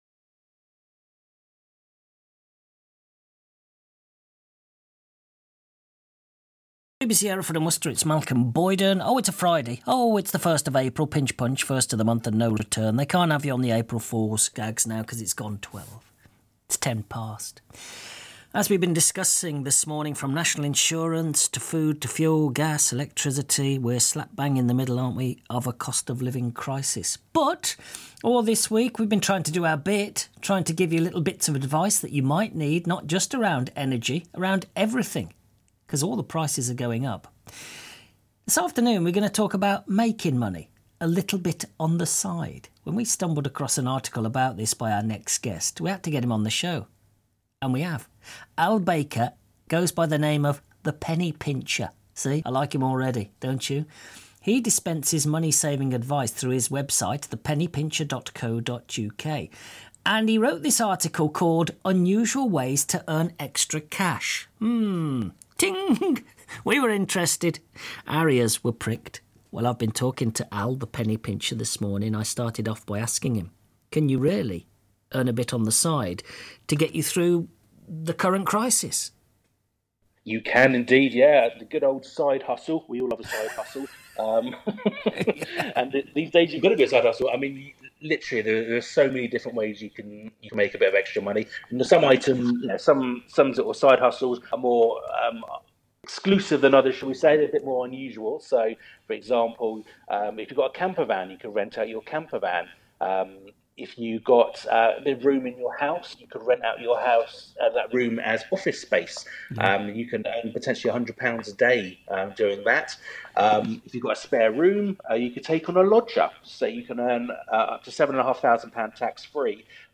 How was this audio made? You can listen to the interview below, Audio kicks in after about 7 seconds!